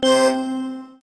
timer-second.mp3